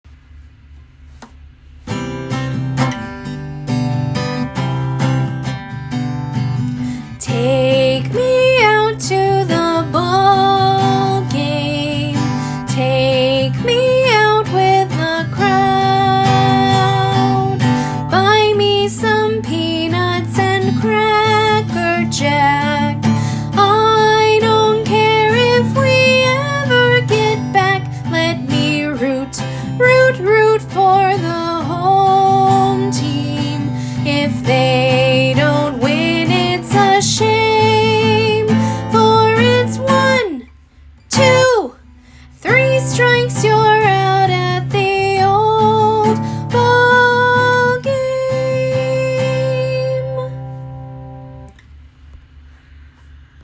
Younger children will be entranced by the beautiful illustrations, older children will be excited to learn about a favorite American sport, and you will love singing this simple and catchy classic!